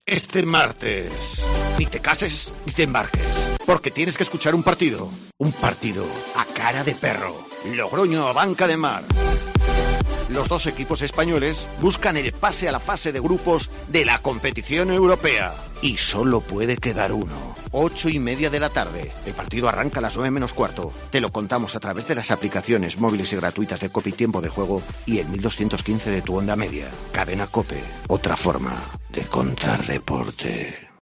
Escucha la cuña promocional del partido BM Logroño-Abanca Ademar el día 21-09-21 a las 20:45 h en el 1.215 OM